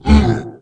sound / golem / pain1.wav
pain1.wav